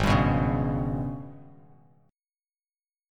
G#mM7bb5 Chord